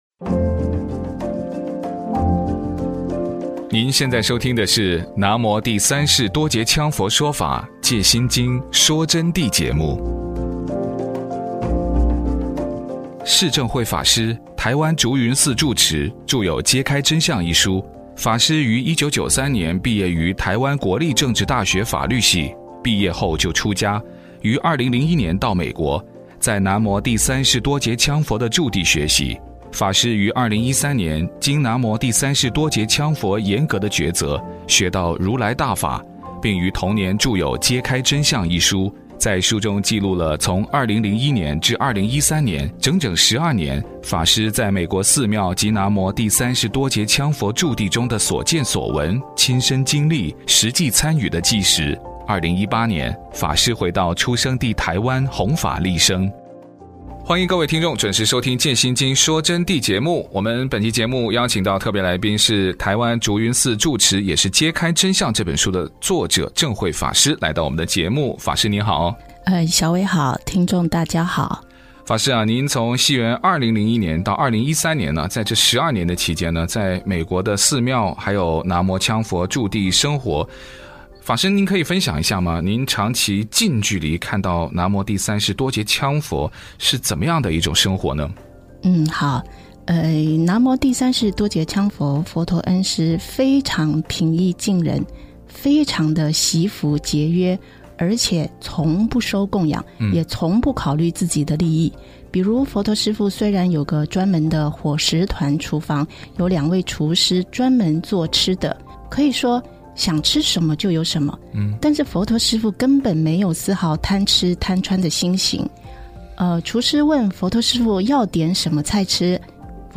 佛弟子访谈（四十三）南无羌佛生活极其简朴却将所得布施利众及创作韵雕发生的圣迹